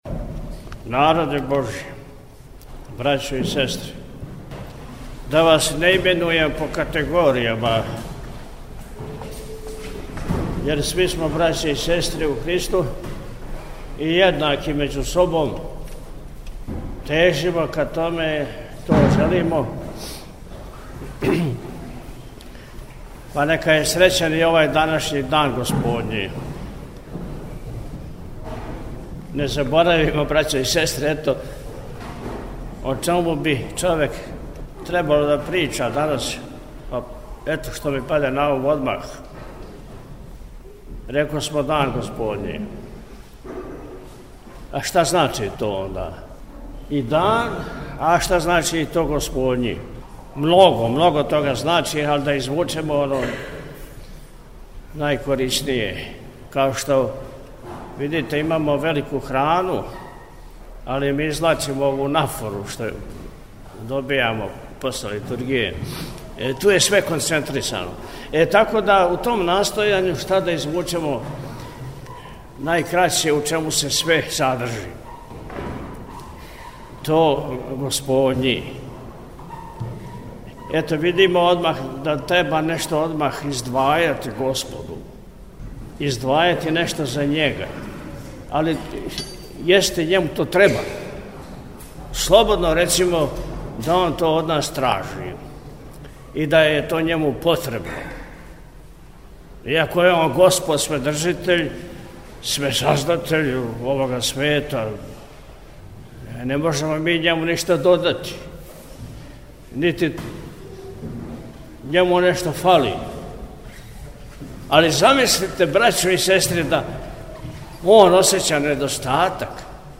У Недељу трећу по Духовима, 29. јуна 2025. године, Његово Високопреосвештенство Архиепископ и Митрополит милешевски г. Атанасије служио је Свету архијерејску Литургију у Саборном храму [...]
Prijepolje-Nedelja.mp3